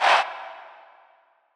NB - REDD CHANT.wav